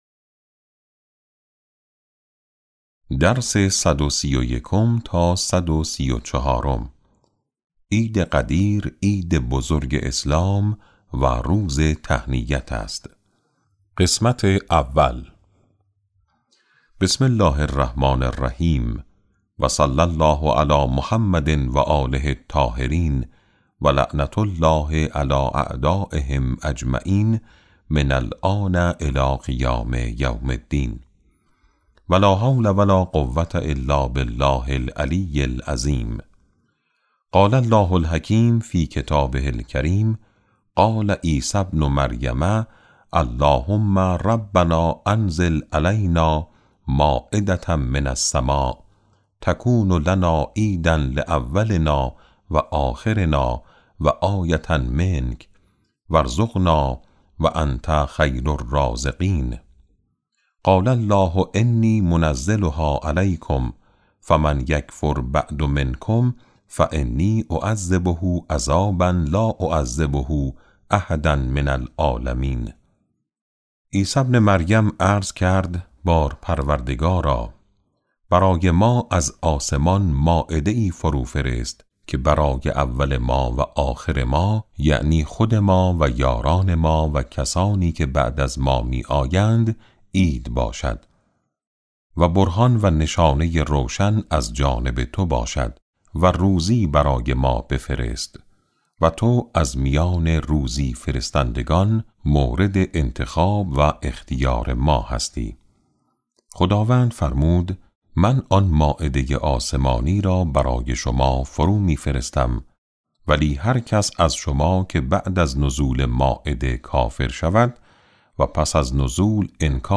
کتاب صوتی امام شناسی ج9 - جلسه9